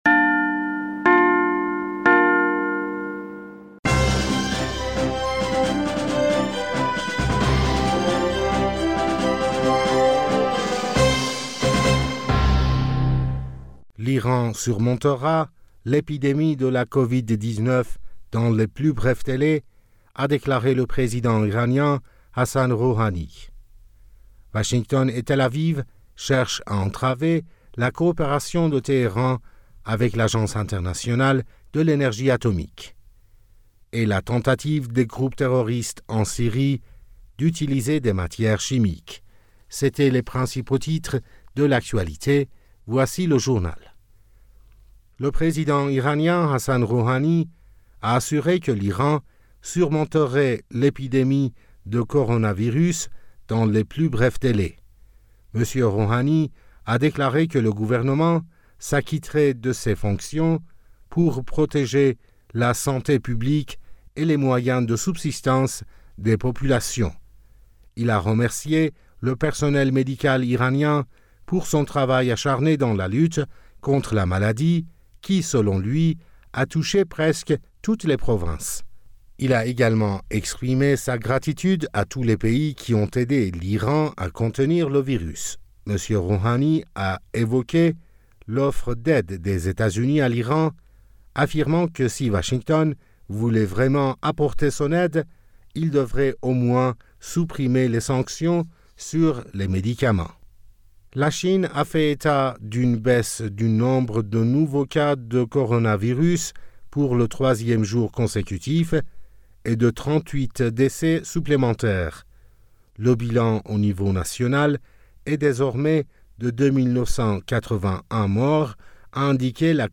Bulletin d'information du 5 mars 2020